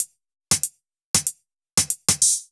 Index of /musicradar/ultimate-hihat-samples/95bpm
UHH_ElectroHatD_95-05.wav